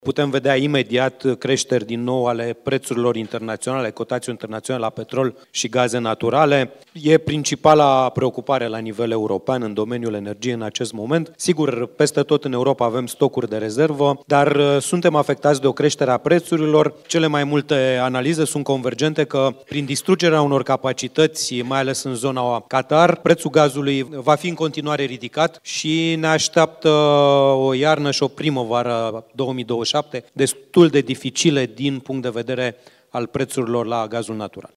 Cotația la petrol și gaze este principala preocupare la nivel european în domeniul energiei, a declarat oficialul în cadrul celei de-a opta ediții a Forumului Energiei.
Secretarul de stat de la Ministerul Energiei, Cristian Bușoi: „Suntem afectați de o creștere a prețurilor”